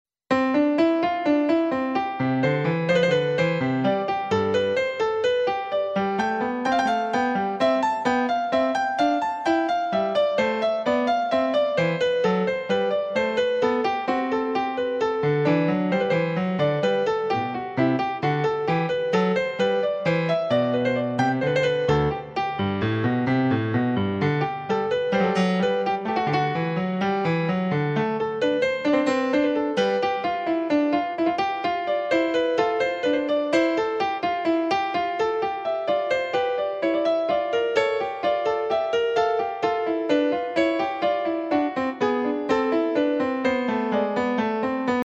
Downloadable Instrumental Track